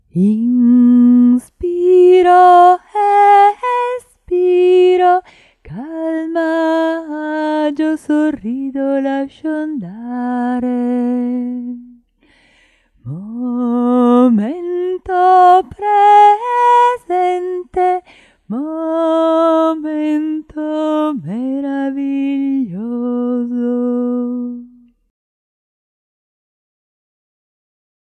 Canzoni in italiano
4 – “Canzoni distensive per il Rilassamento Profondo”: